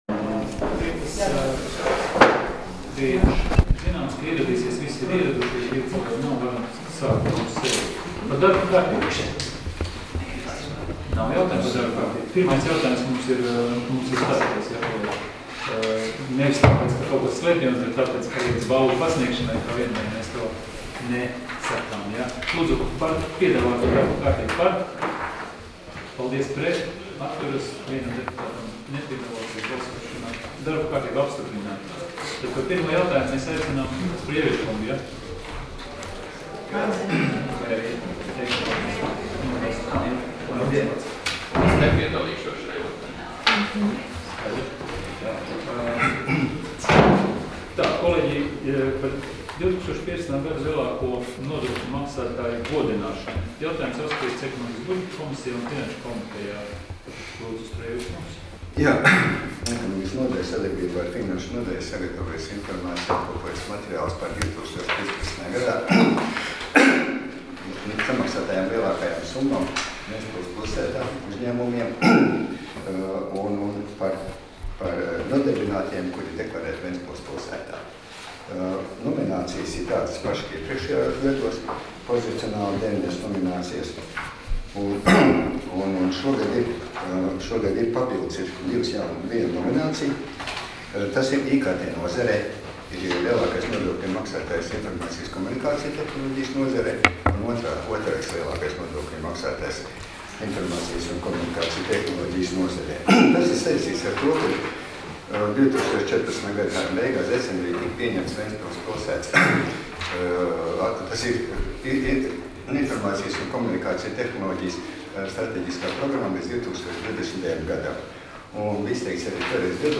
Domes sēdes 27.05.2016. audioieraksts